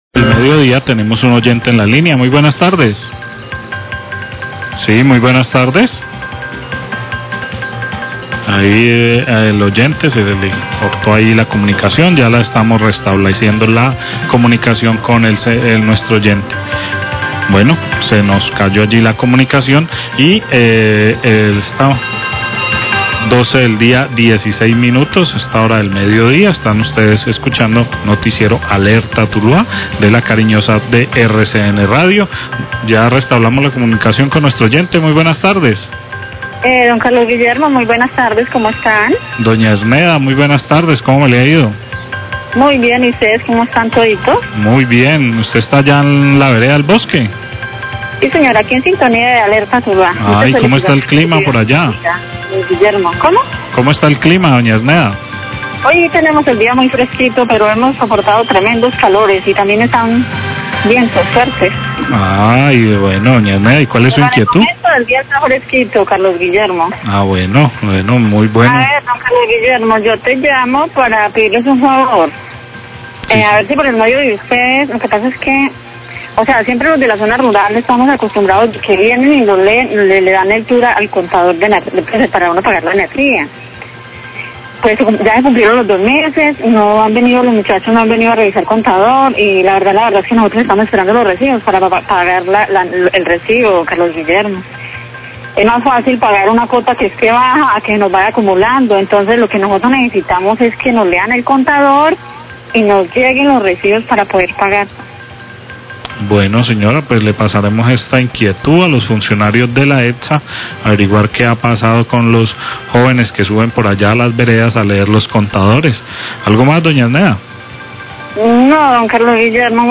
Radio
Oyente